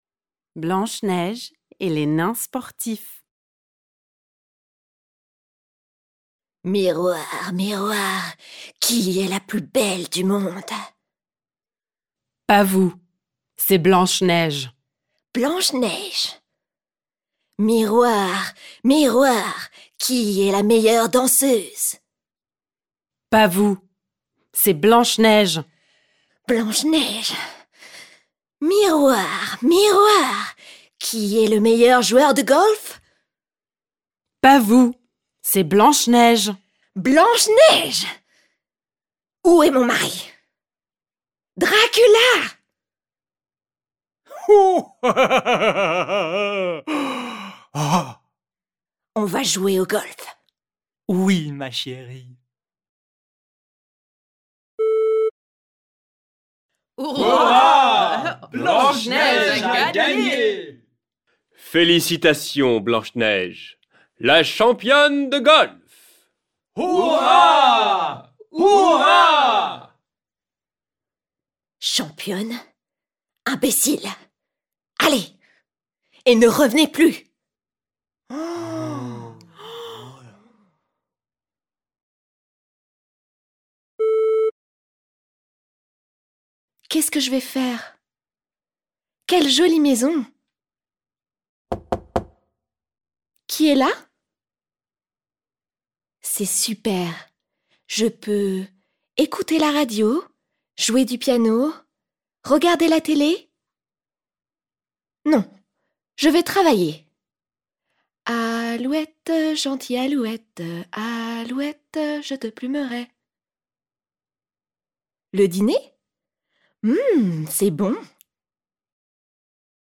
The free downloadable audio files of the plays being performed by native French speakers provide invaluable listening practice and will help students develop good pronunciation.